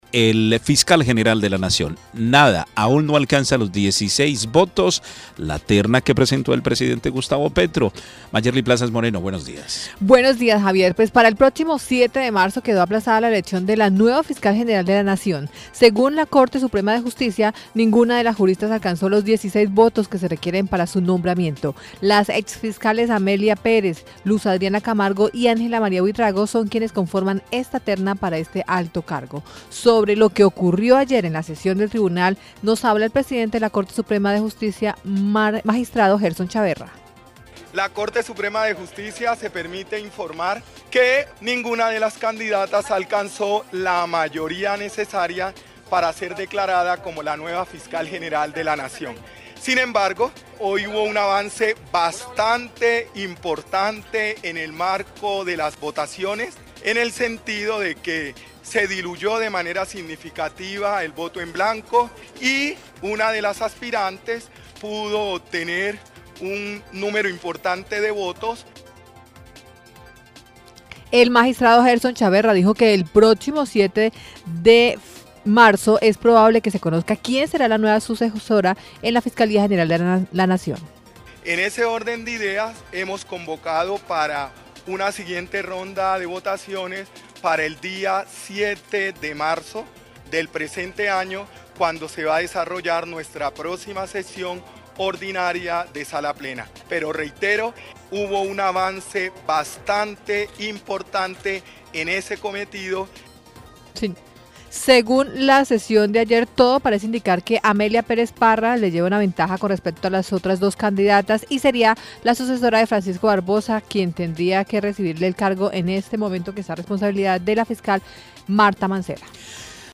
Sobre lo que  ocurrió en la sesión  del tribunal habla presidente de la Corte Suprema, el magistrado Gerson Chaverra.